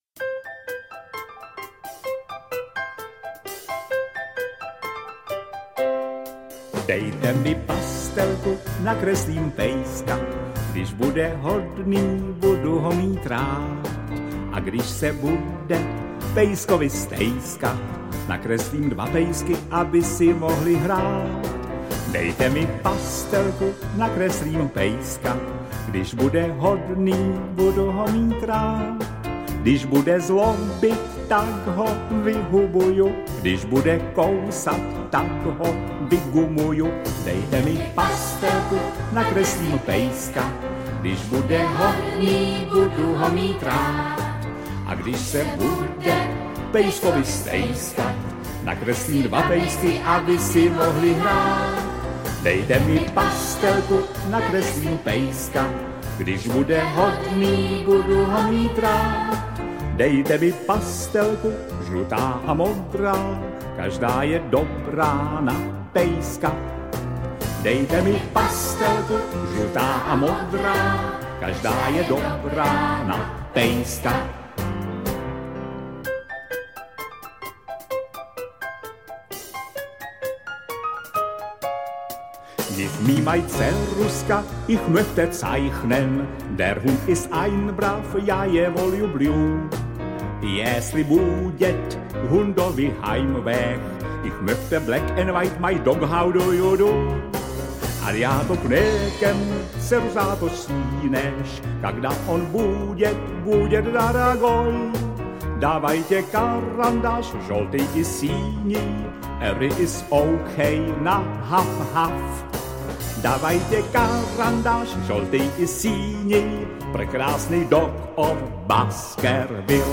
Dejte mi pastelku, nakreslím pejska audiokniha
Ukázka z knihy